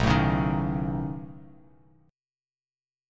B9 Chord
Listen to B9 strummed